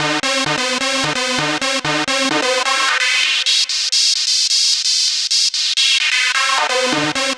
Lead 130-BPM D.wav